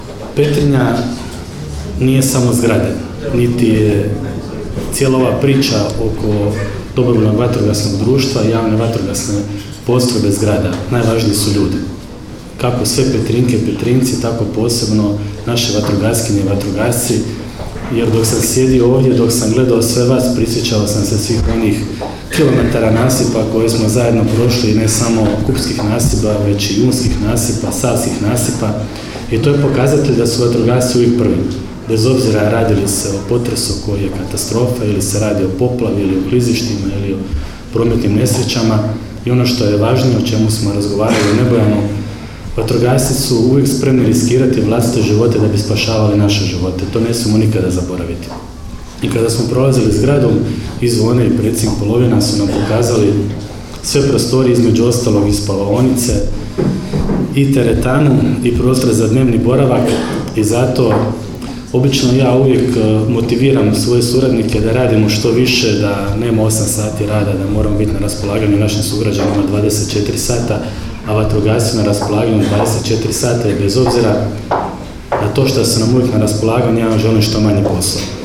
Svečanim presijecanjem vrpce otvorene su novoobnovljene prostorije DVD-a Petrinja.
Sisačko-moslavačke županije Ivan Celjak je podsjetio na veliku ulogu vatrogasaca u cijeloj županiji.